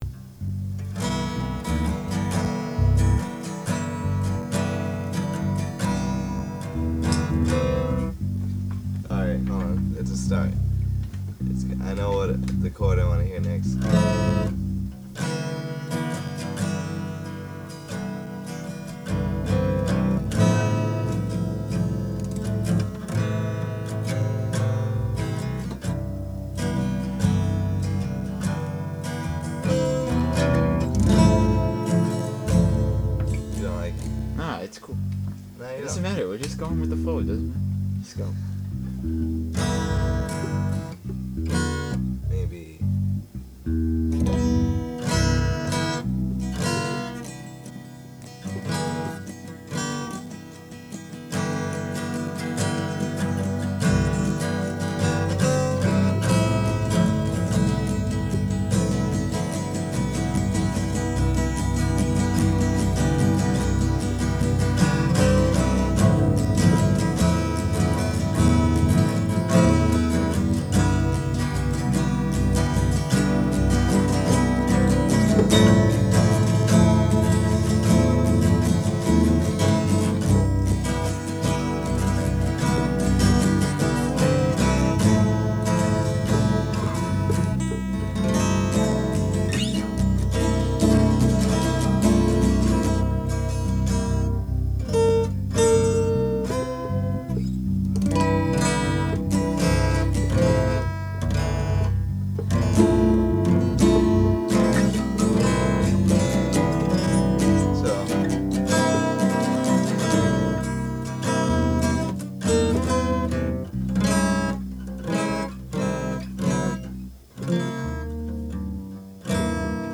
Excerpt from a late-night writing session (acoustic, Short Wave) · SubModern Audio Archives
jam